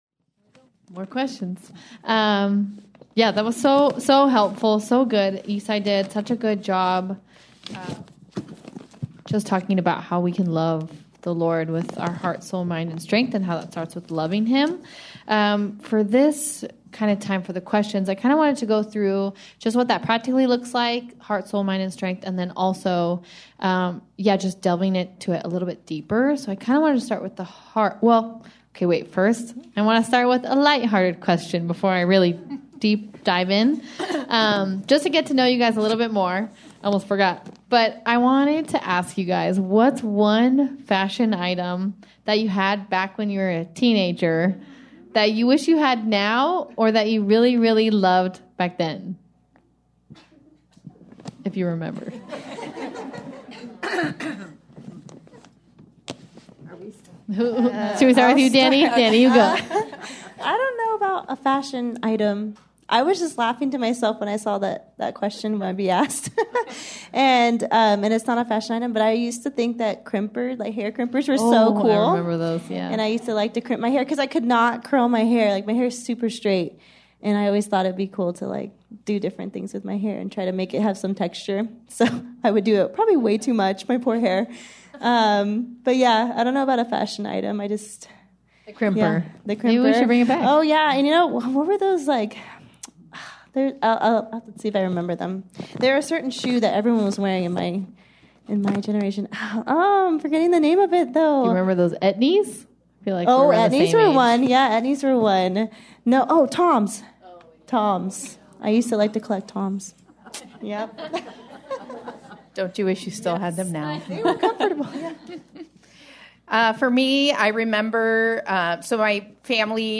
Q&A Panel, Part 2